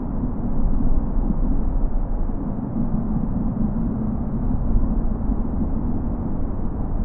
SFX - Main engine thrust.ogg